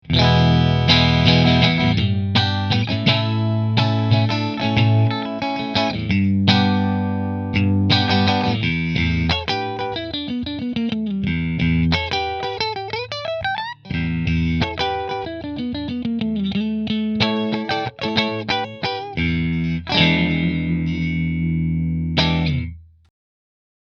• Three Custom Wound P90s
New Orleans Guitars Voodoo Quilt Middle Middle Through Fender